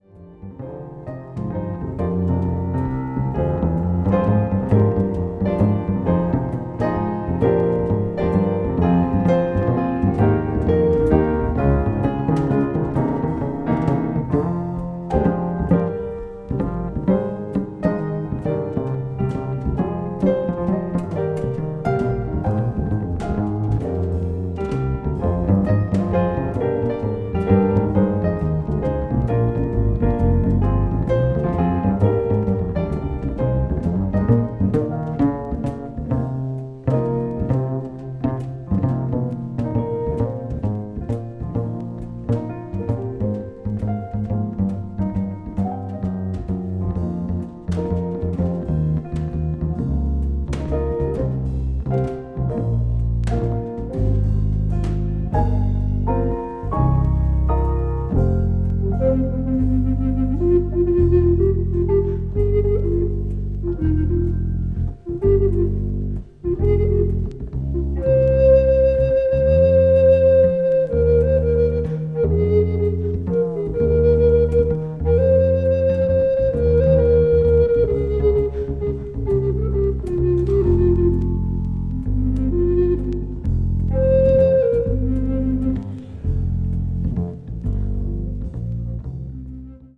わずかにチリノイズが入る箇所あり
violin,ss,recorder